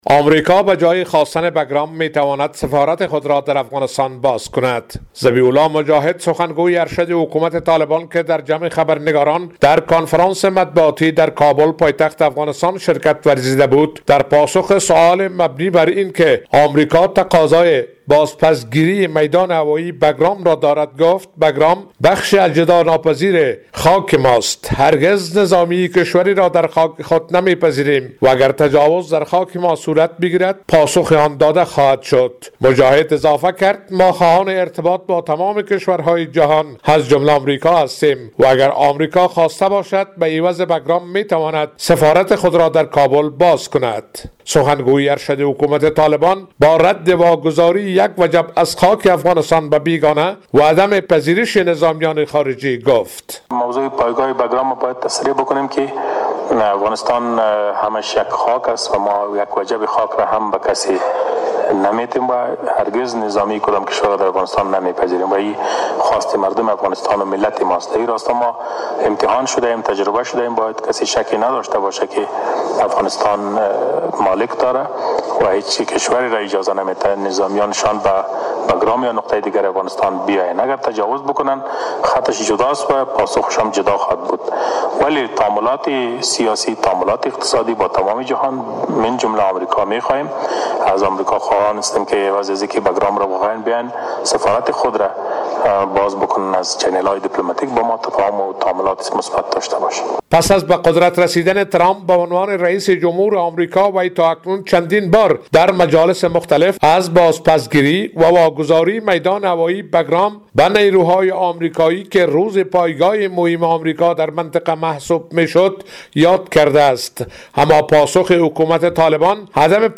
در جمع خبرنگاران در کنفرانس مطبوعاتی در کابل پایتخت افغانستان